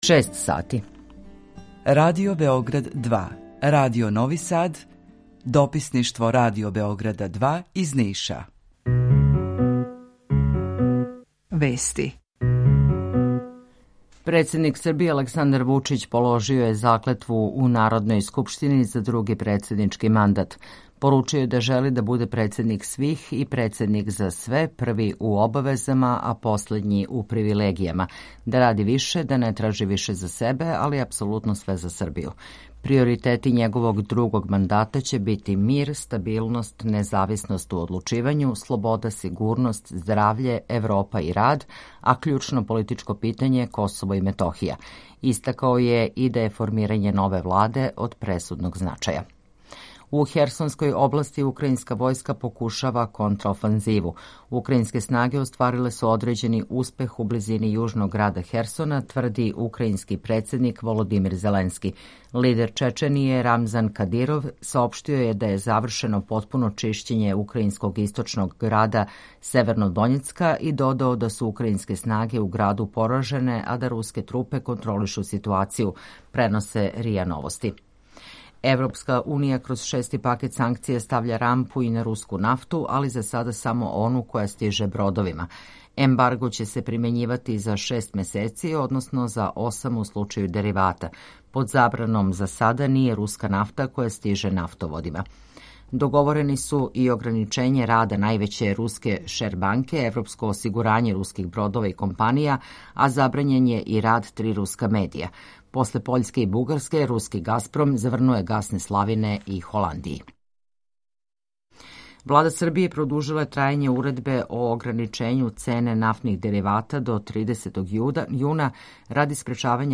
Укључење Радио Грачанице
Јутарњи програм из три студија
У два сата, ту је и добра музика, другачија у односу на остале радио-станице.